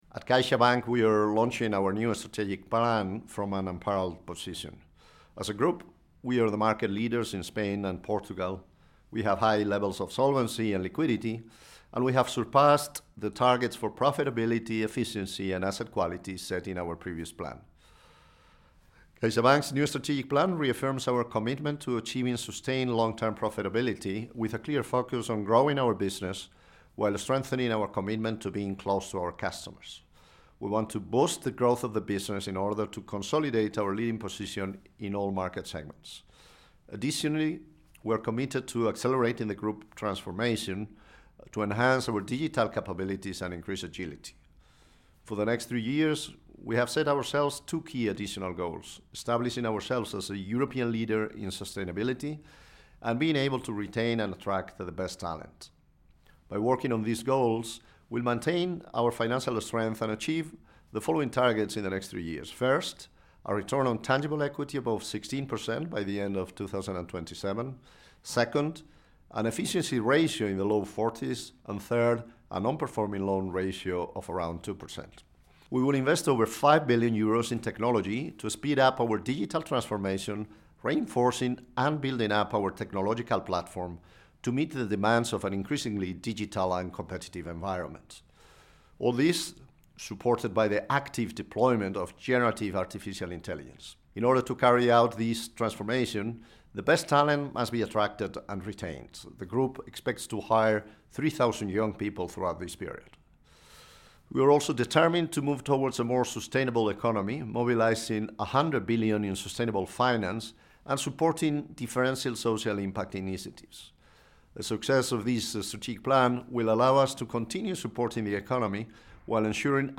Quote from CaixaBank's CEO, Gonzalo Gortázar